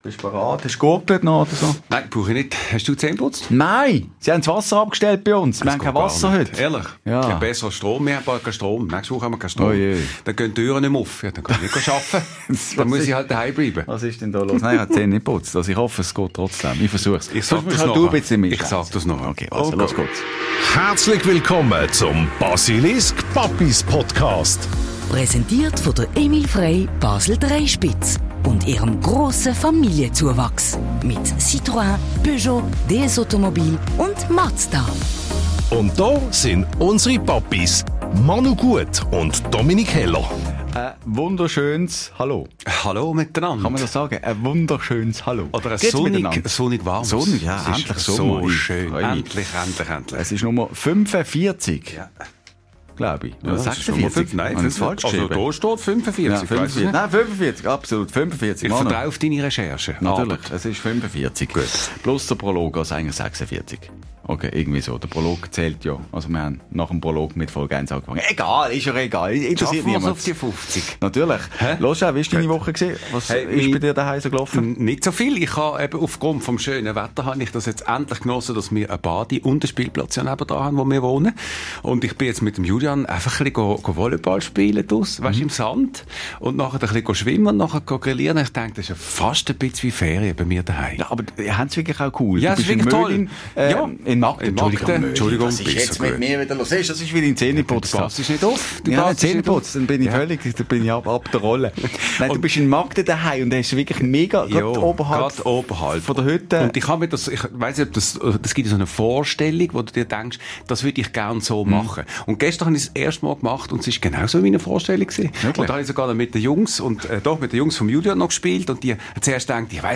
Ein ehrlicher Talk über überforderte öffentliche Schulen, ratlose Eltern, nötige Reformen – und die Frage: Welche Schule passt wirklich zu meinem Kind?